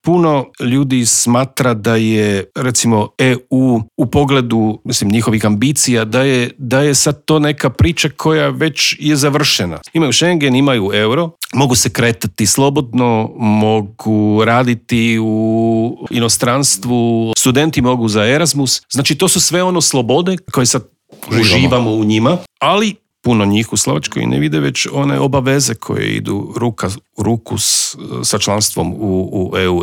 ZAGREB - U intervjuu tjedna ugostili smo veleposlanika Republike Slovačke Petera Suska.